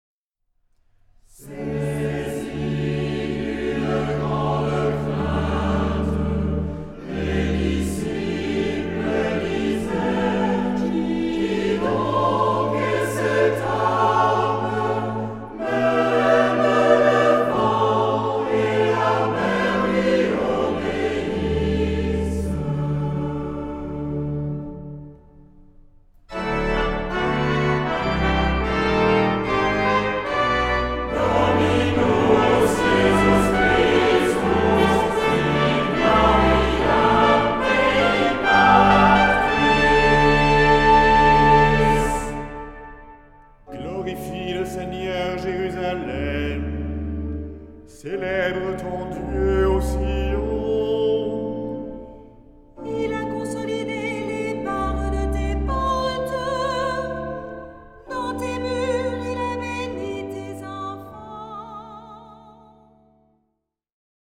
Genre-Style-Form: troparium ; Psalmody
Mood of the piece: collected
Type of Choir: SATB  (4 mixed voices )
Instruments: Organ (1) ; Melody instrument (1)
Tonality: D sharp minor ; D major
Trompette